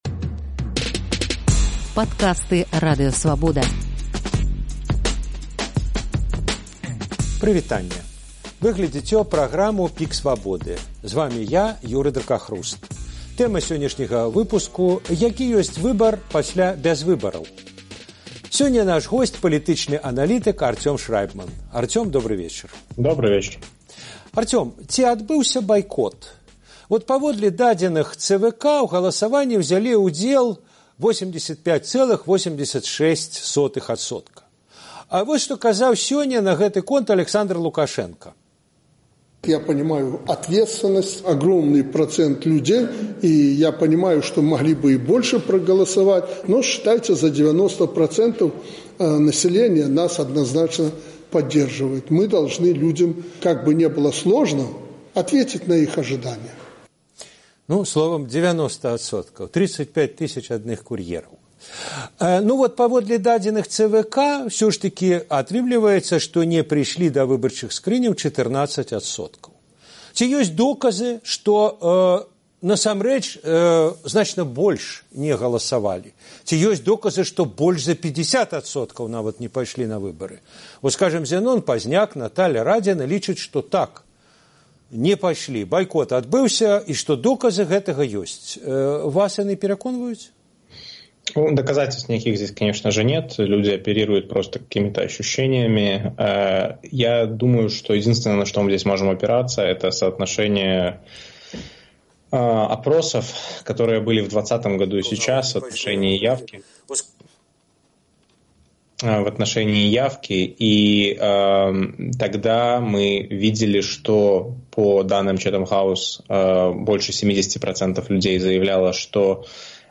ПіК Свабоды» адказвае палітычны аналітык Арцём Шрайбман